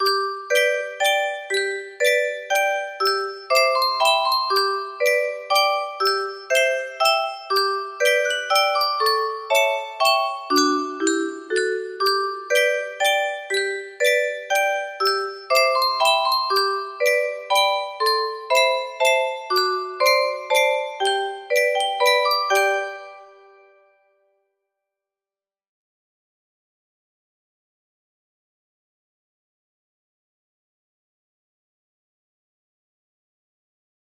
wtf music box melody
Full range 60